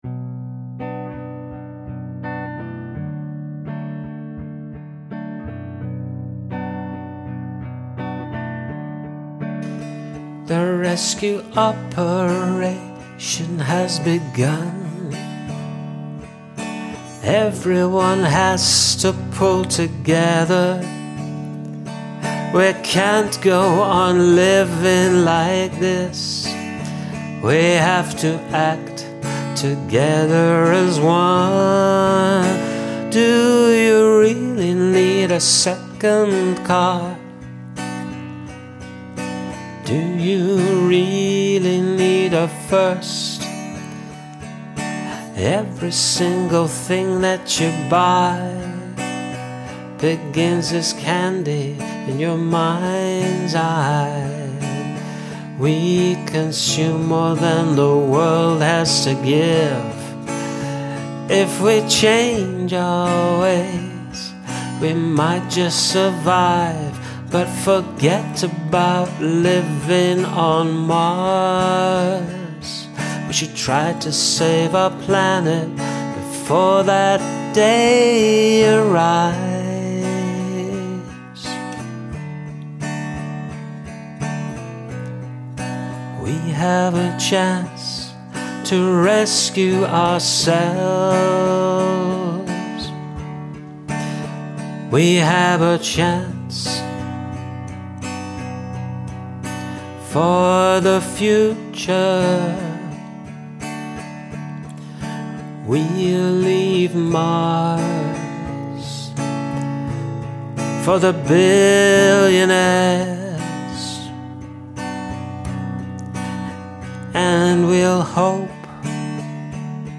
Bigger scale this time
Yeah, nicely protesty.
This one is more of a protest/ social commentary song than your first one and is all the better for it.